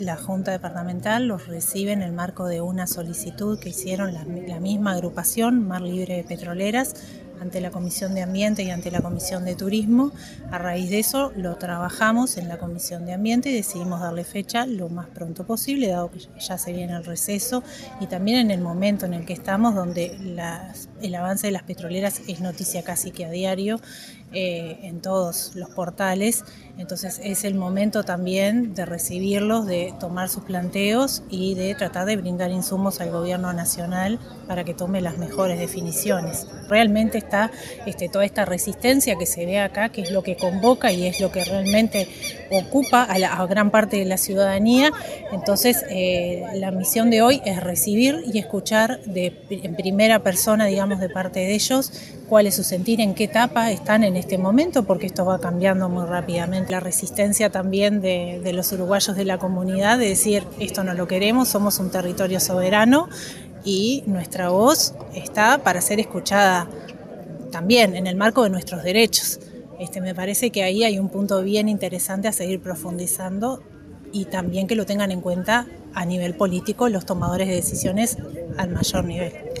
La edila del Frente Amplio e integrante de la Comisión de Ambiente de la junta, Melissa Sturla, dialogó con Radio Uruguay sobre los motivos que llevaron a los ediles departamentales a aceptar el pedido de las organizaciones y sobre el rol que tienen para la comunidad en un tema de tanta sensibilidad.